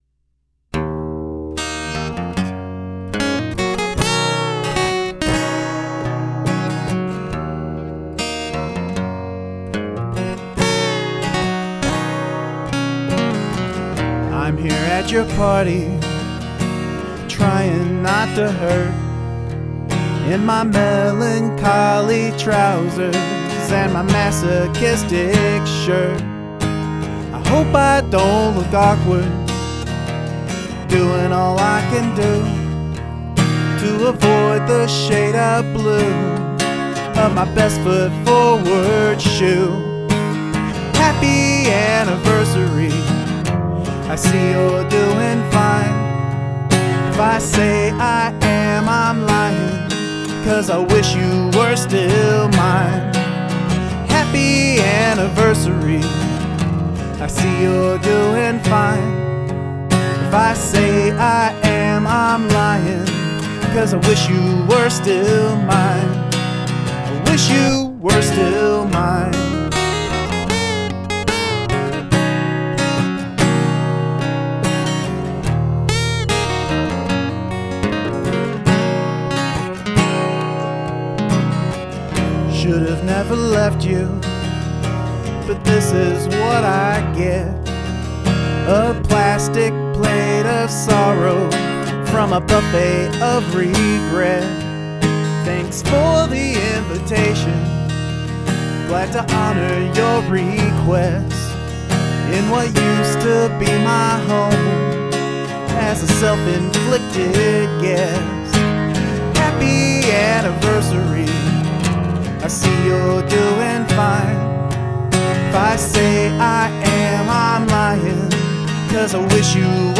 I love this song even though it's a downer.
That's a great sounding guitar.
Nice unresolved ending.